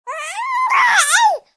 babyscream2.ogg